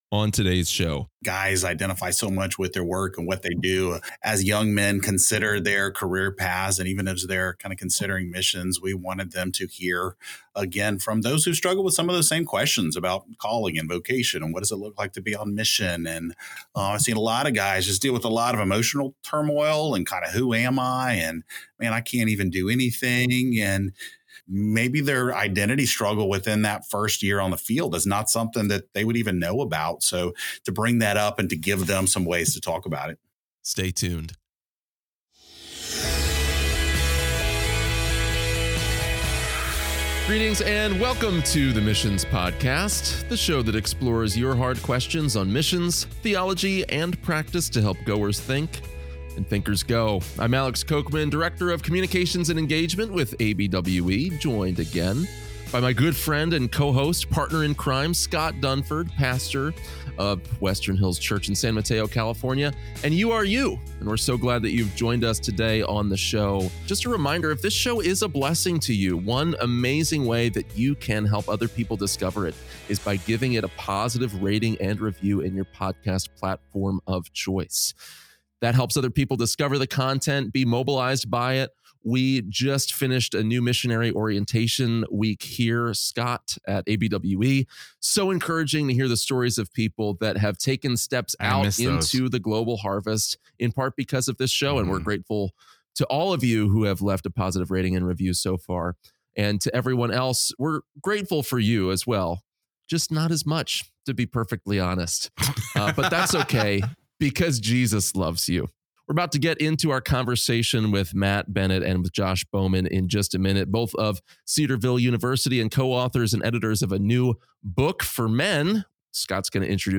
What To Know Before You Go: A Conversation